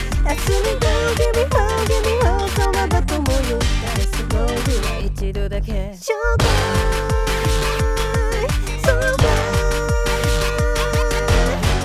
実際に私が歌ってみたハモリ有り無しの素音源(mix前のもの)を用意したので聴き比べてみて下さい。
▼ハモリ有り
ハモリ有りの方が圧倒的に良いですよね！